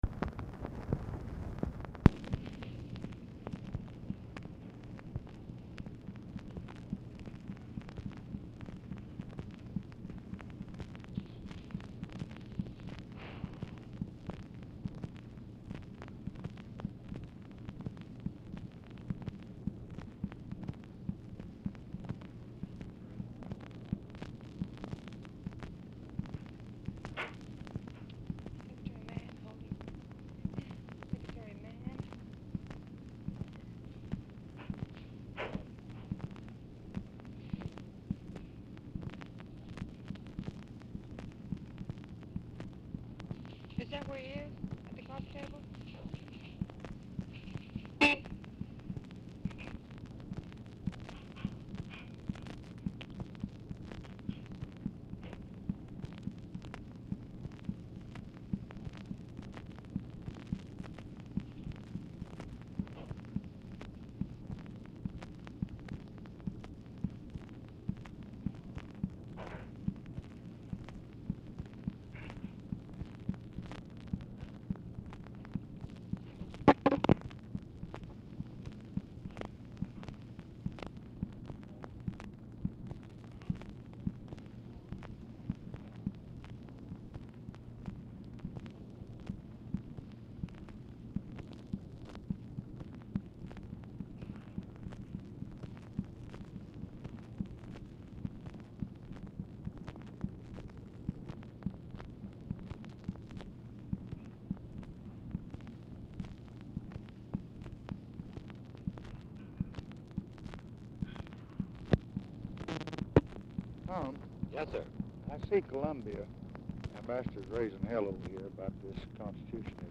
Telephone conversation # 8641, sound recording, LBJ and THOMAS MANN, 8/26/1965, 4:35PM | Discover LBJ
LBJ PUTS MANN ON HOLD DURING CONVERSATION TO RETRIEVE PRESS TICKER
Format Dictation belt
Oval Office or unknown location
Specific Item Type Telephone conversation